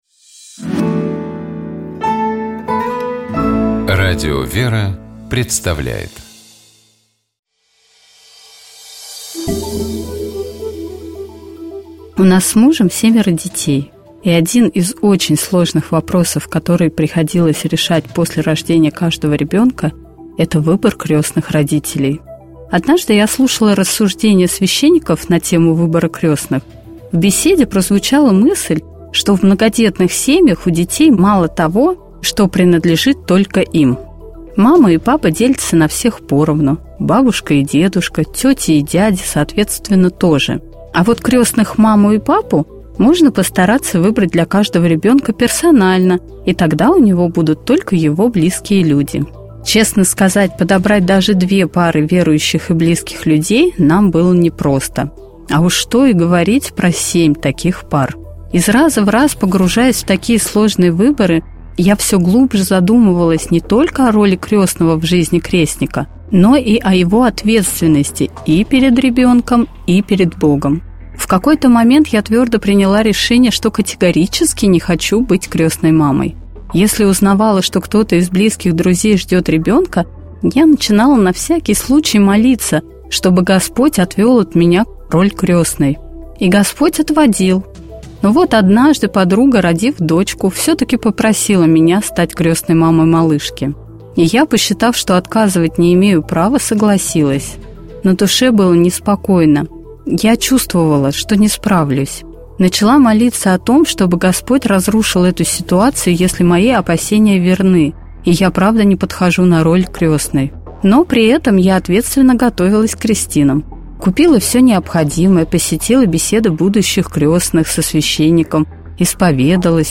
Псалом 111. Богослужебные чтения